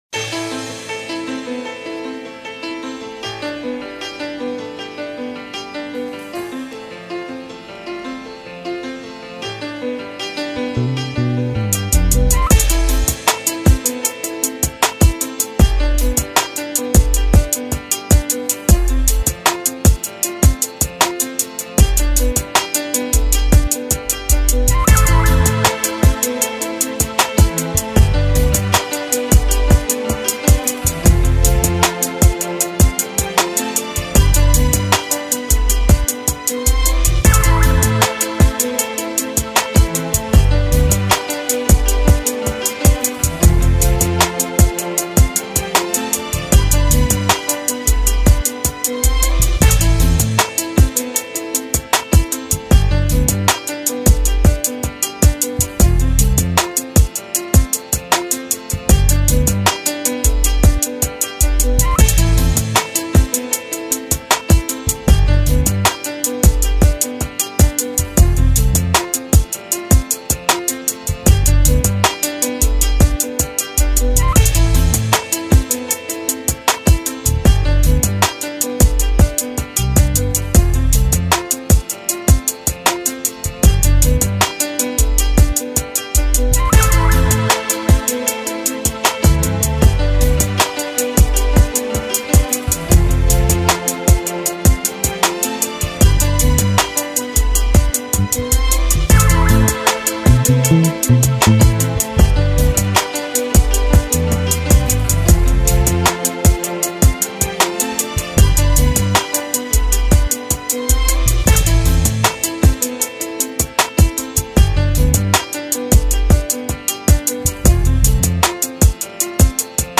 МИНУС, строго!!!
minus_3raund.mp3